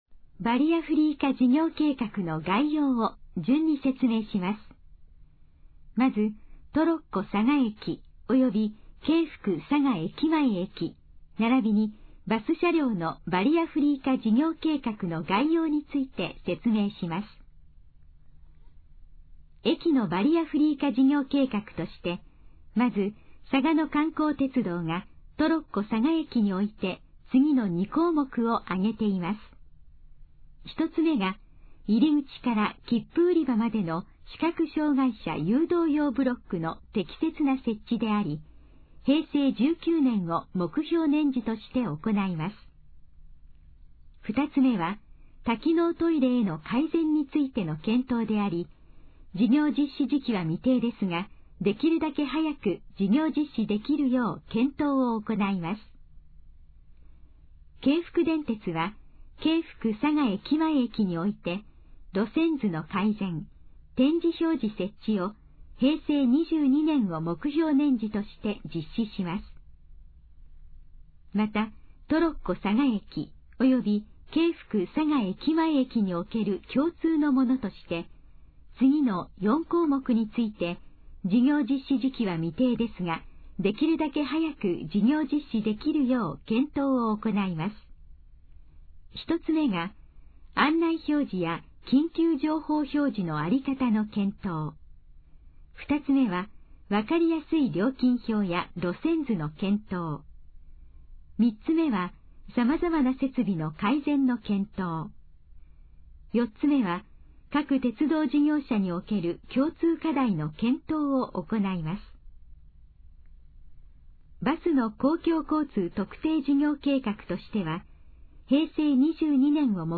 以下の項目の要約を音声で読み上げます。
ナレーション再生 約278KB